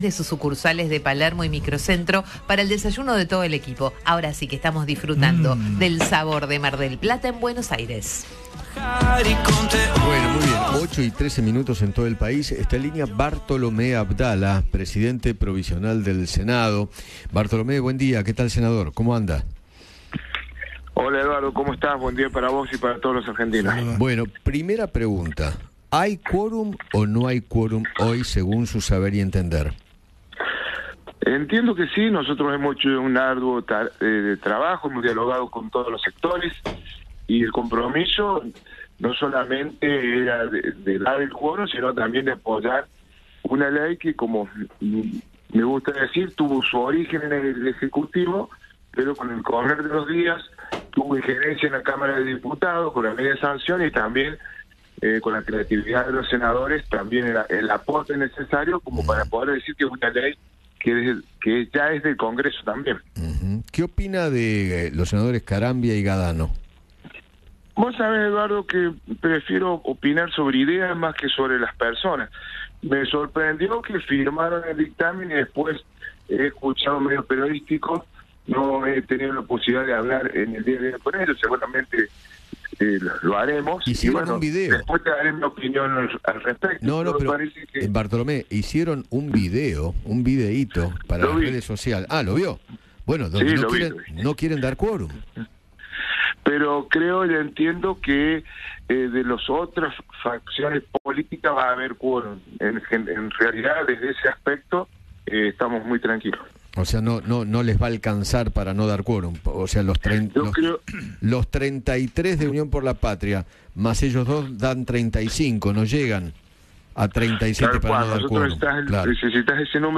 Bartolomé Abdala, presidente provisional del Senado, dialogó con Eduardo Feinmann sobre el debate de la Ley Bases en la Cámara alta.